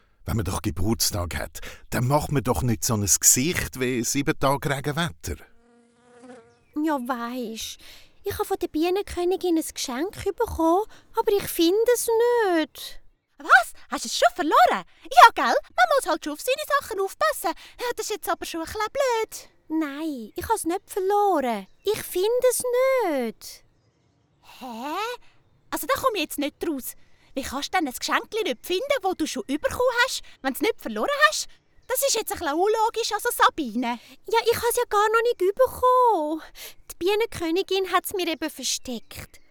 Dialekt Hörspiel